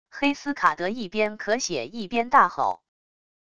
黑斯卡德一边咳血一边大吼wav音频